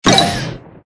Mole_Stomp.ogg